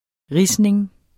Udtale [ ˈʁisneŋ ]